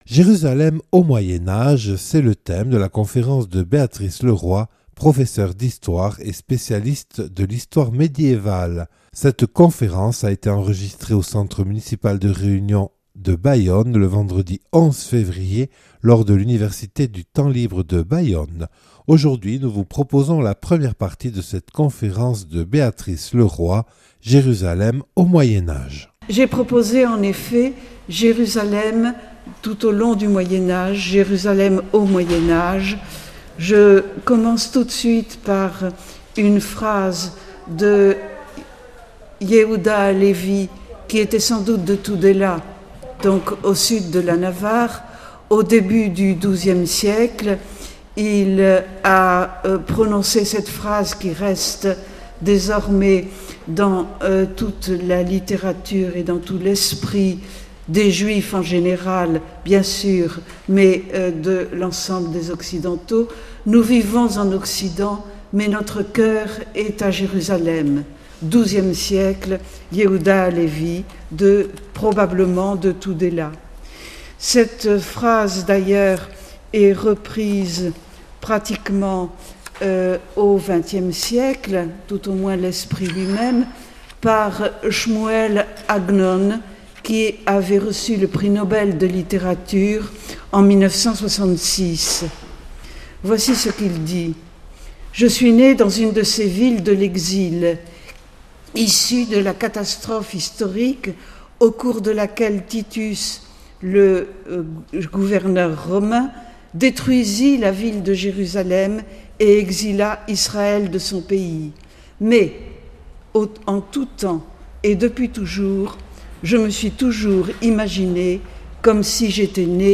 Conférence
(Enregistrée lors de l’Université du Temps Libre de Bayonne le 11 février 2022).